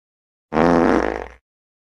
Download Funny Meme sound effect for free.
Funny Meme